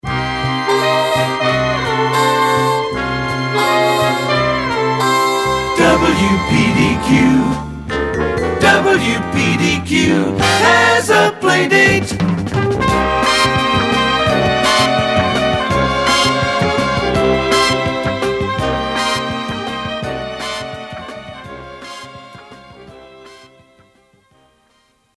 These jingle samples are from my private collection